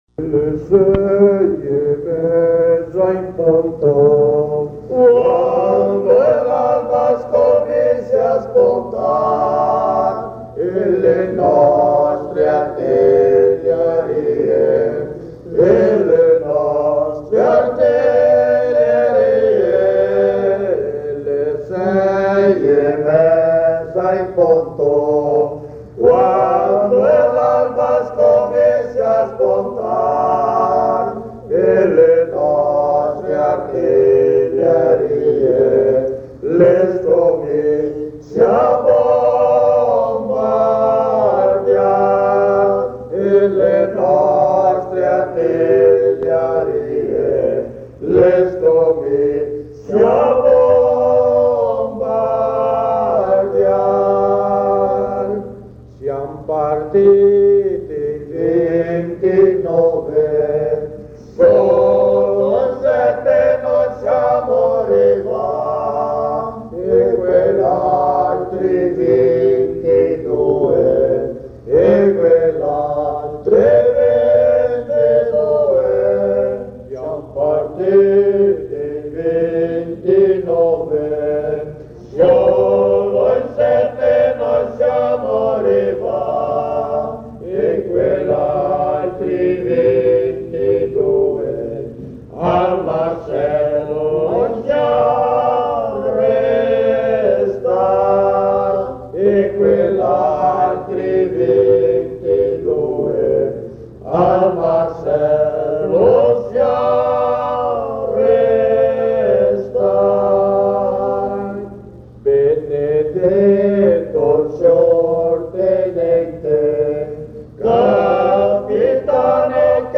Le mame de Araça- Vila Zucchetti-coro mamme Le sei e mesa in punto - Coro - Camargo - RS